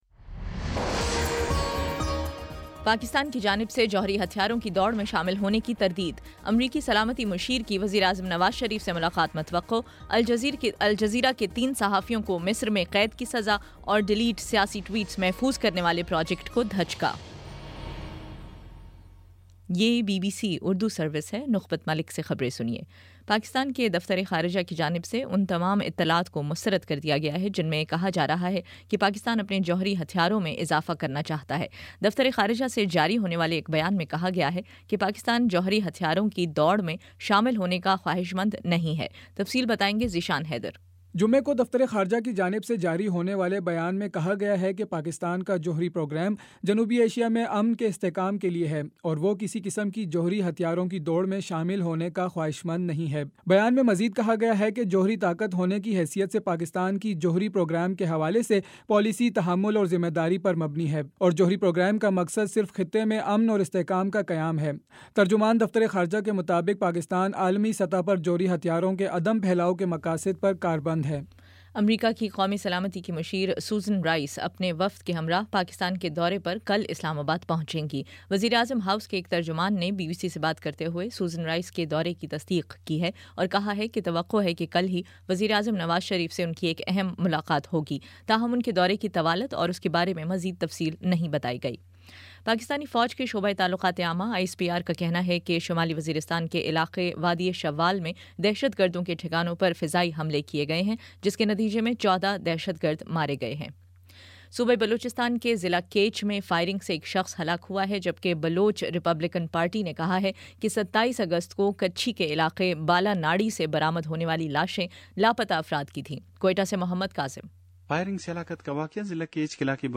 اگست 29: شام پانچ بجے کا نیوز بُلیٹن